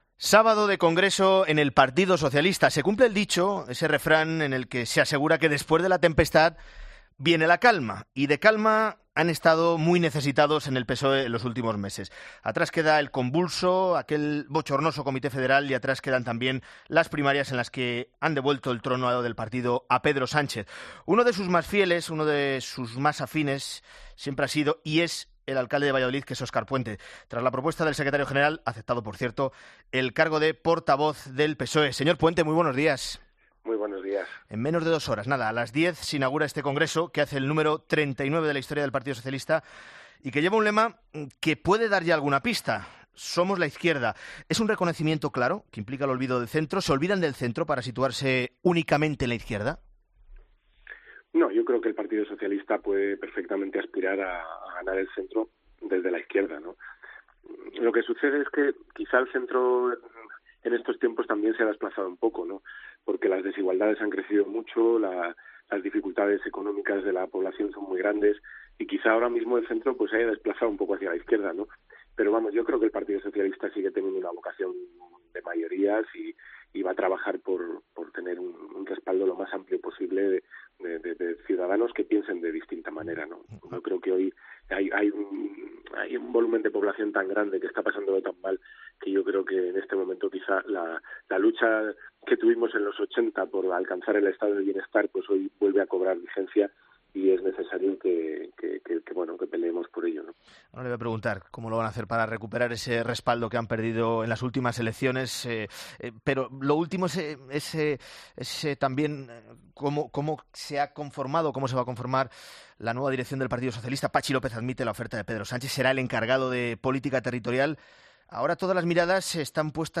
Óscar Puente, portavoz del PSOE, en "La Mañana Fin de Semana"
Entrevista política